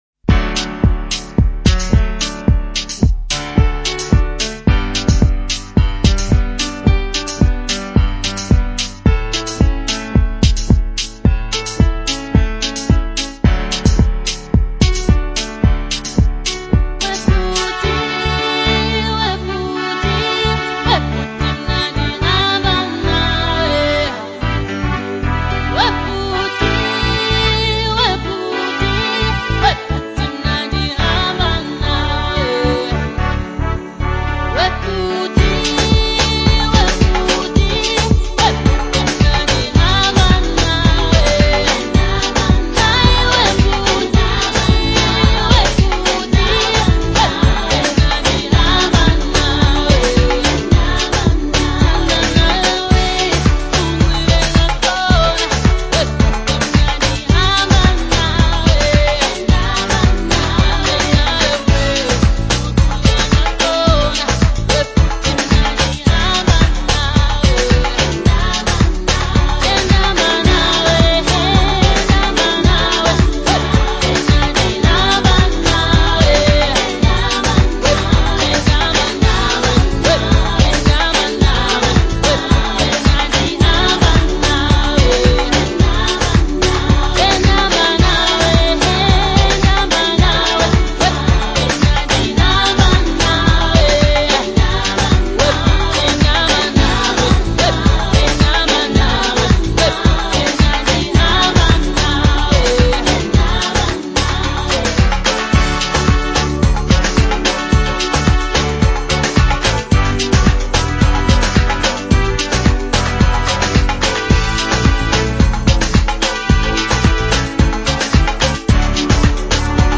catchy rhythm
polished vocal performance